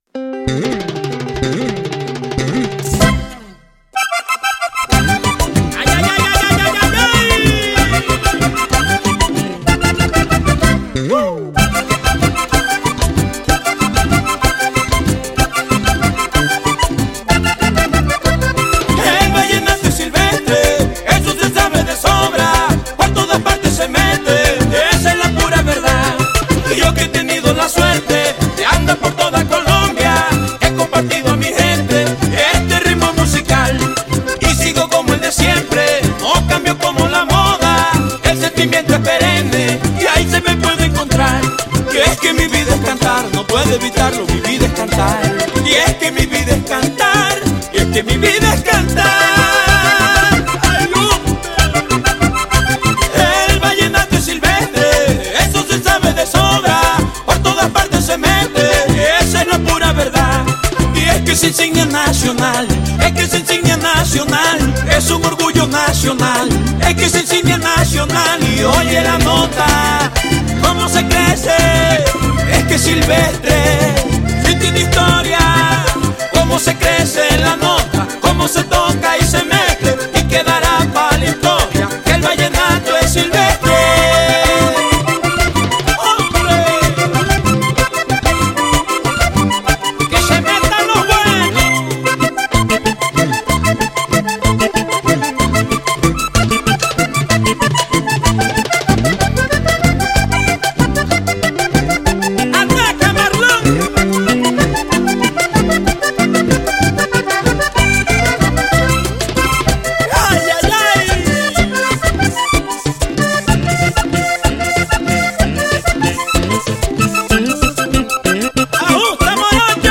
acordeón